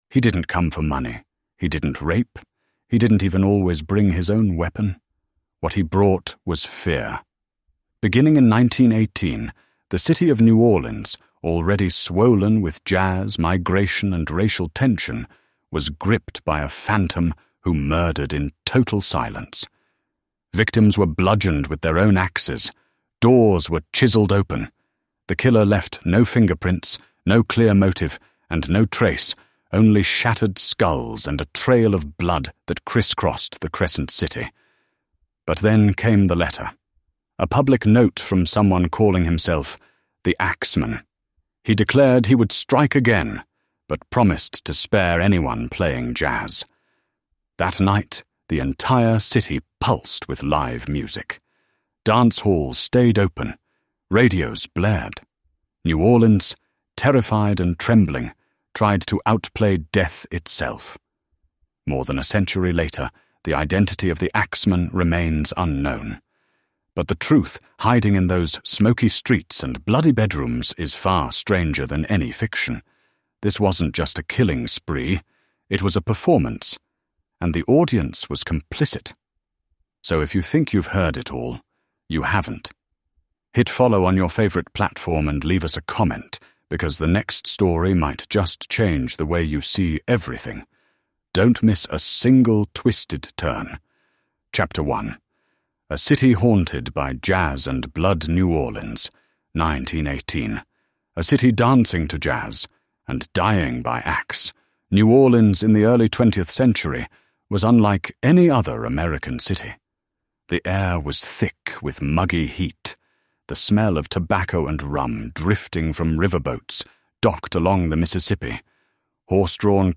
Hear the original letter read aloud.